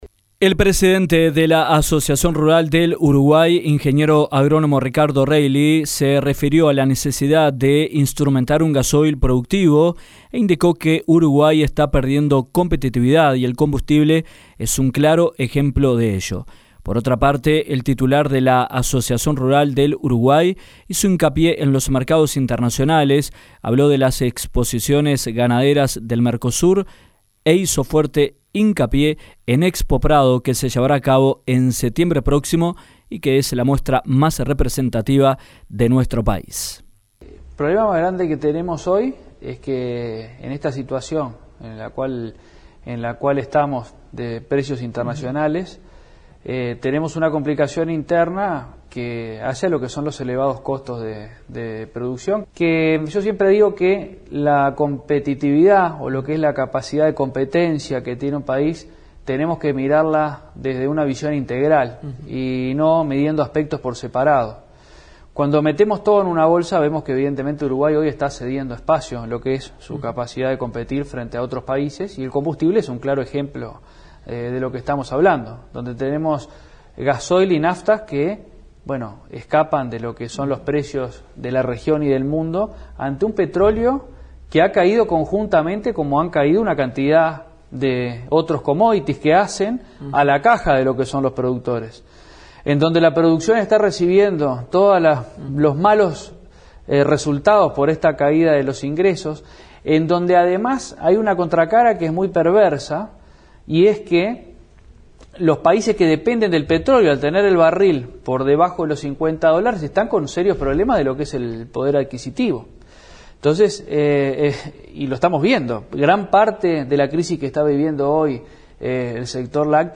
En diálogo con Dinámica Rural